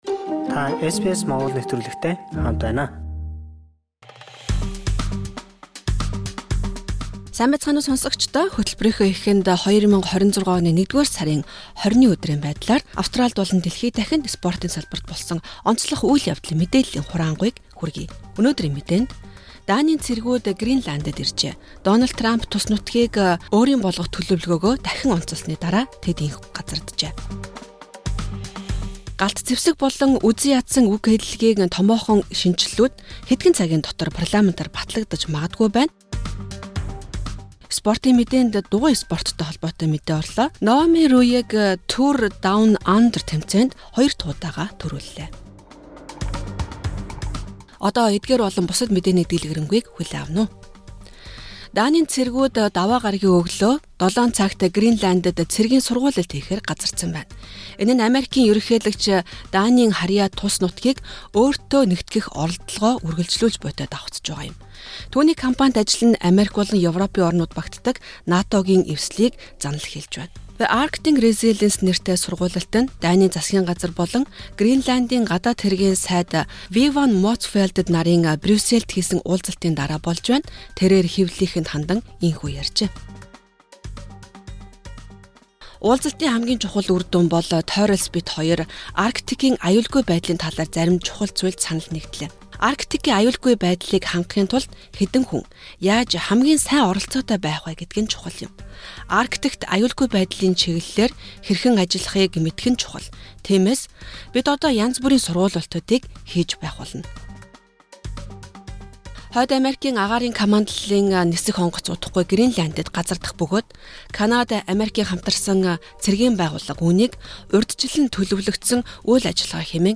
Мягмар гараг бүр хүргэдэг тойм мэдээг хүлээн авна уу.